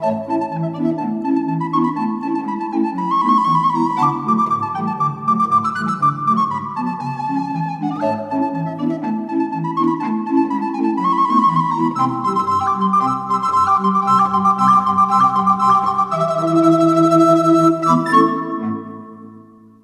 Category: Classical music ringtones